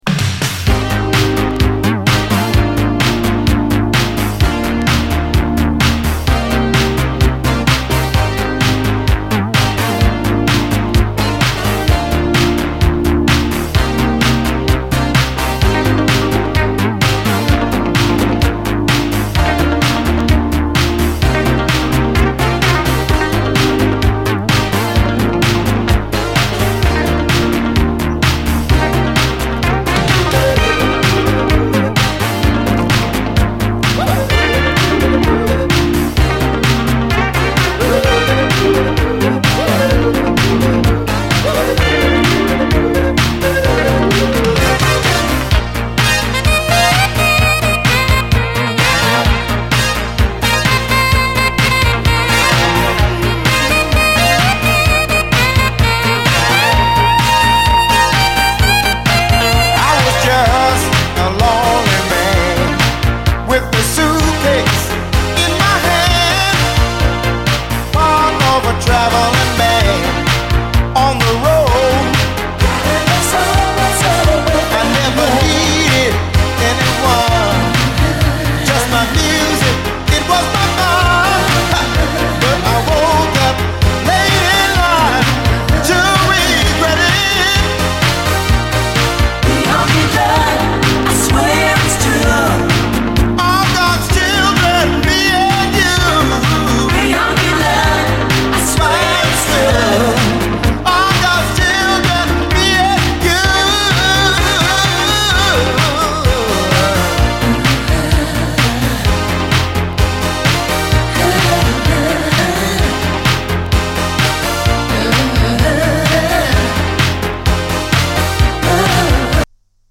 シカゴ産レア・ノーザン〜クロスオーヴァー・ソウル45！
（キックの音がノイズみたいに聴こえますがノイズではなく元々の録音の音です。）